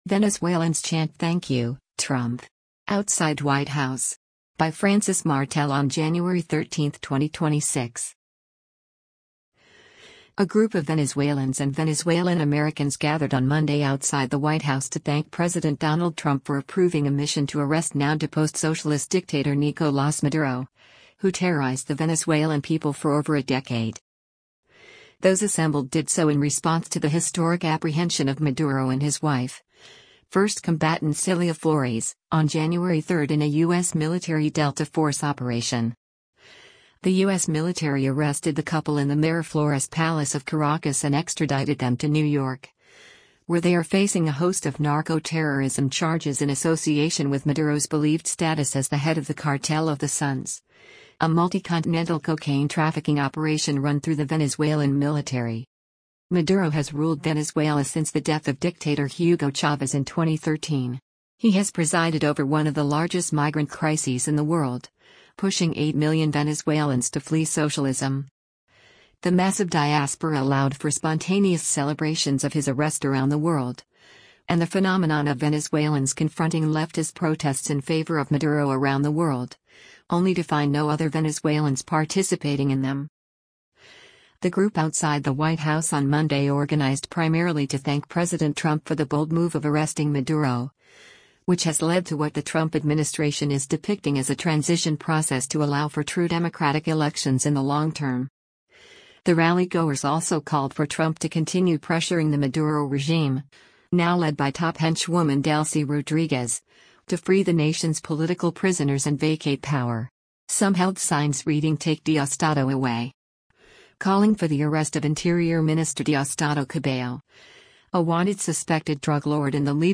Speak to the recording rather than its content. Venezuelans Chant 'Thank You, Trump!' Outside White House